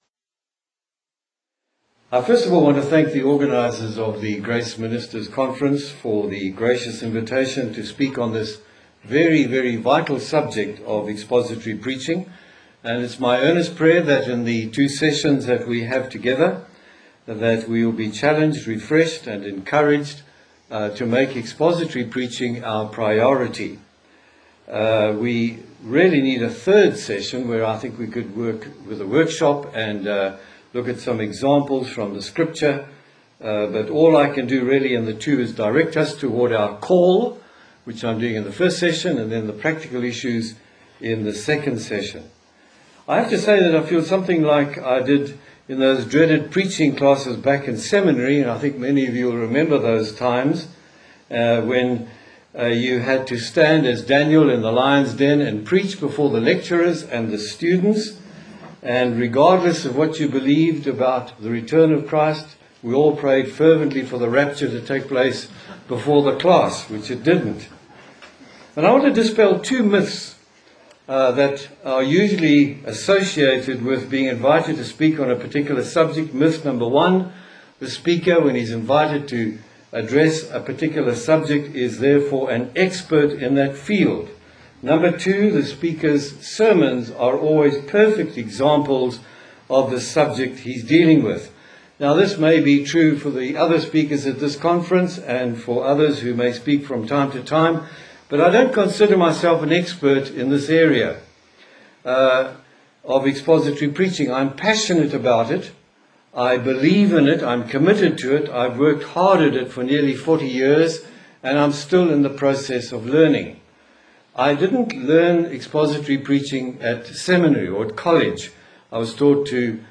2010 Questions & Answers